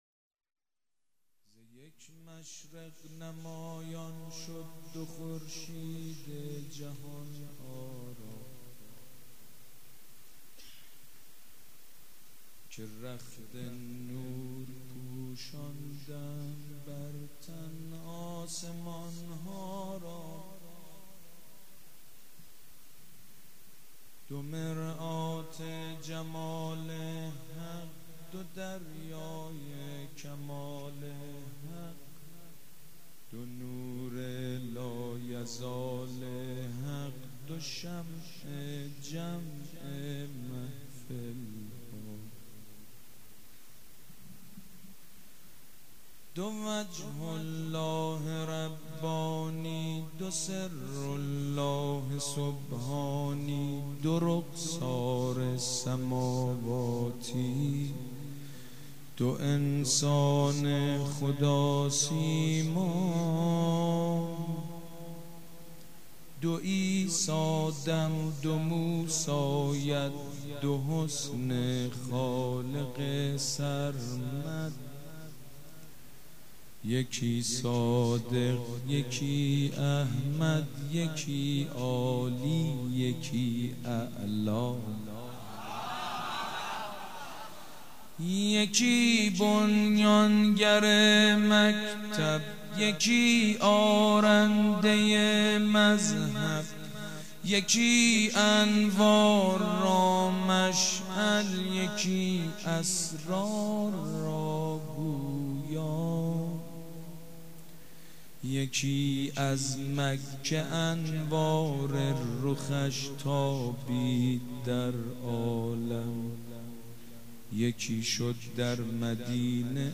شعر خوانی
مداح
ولادت حضرت محمد (ص) و امام صادق (ع)